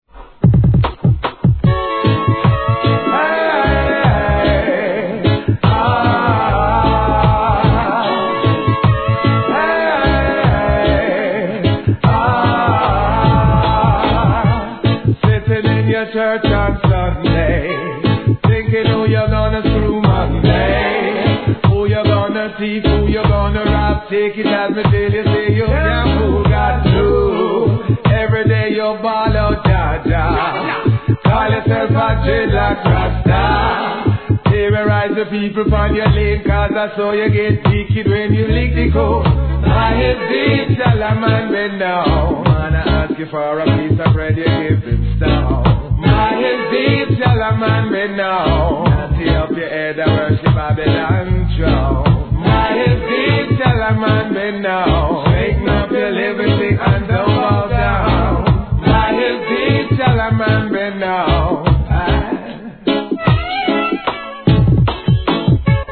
REGGAE
素晴らしいヴォカール・ワークでのスマッシュHIT!!